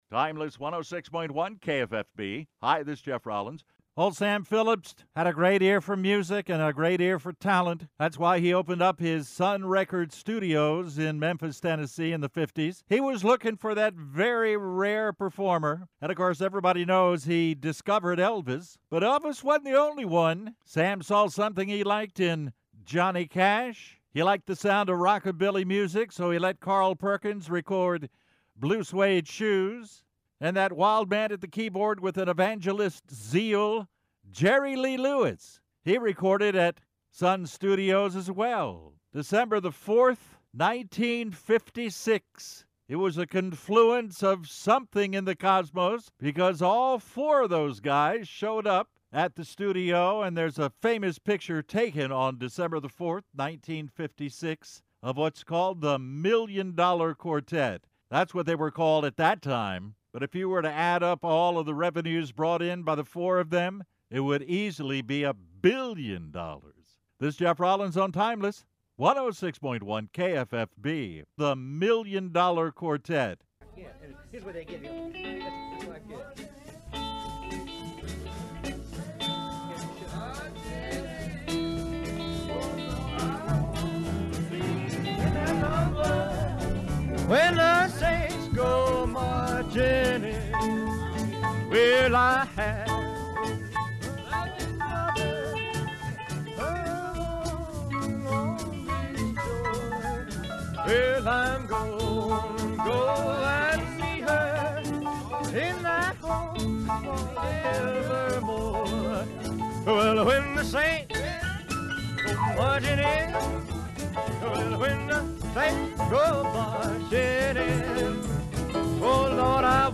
impromptu jam session